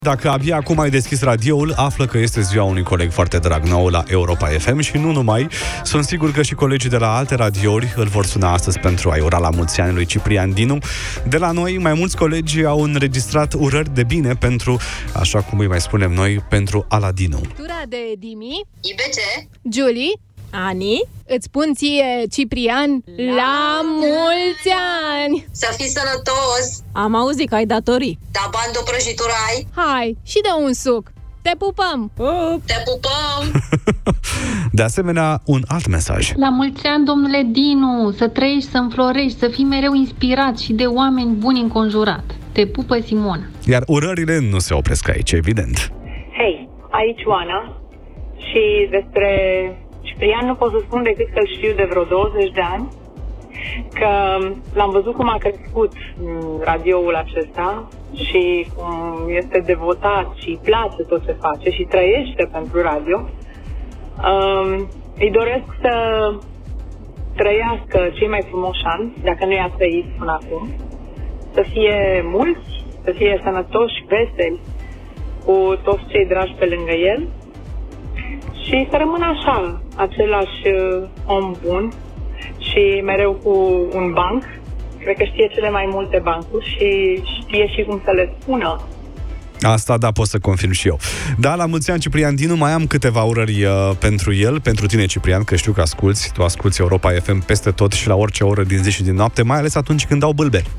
i-au trimis mesaje de La Mulți Ani, în direct: